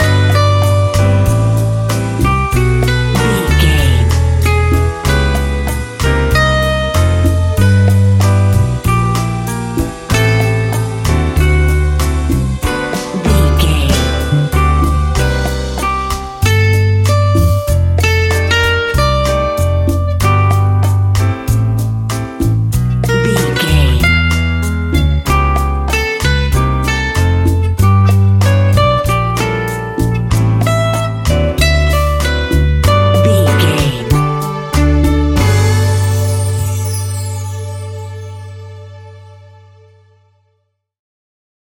An exotic and colorful piece of Espanic and Latin music.
Ionian/Major
glamorous
maracas
percussion spanish guitar
latin guitar